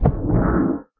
elder_hit1.ogg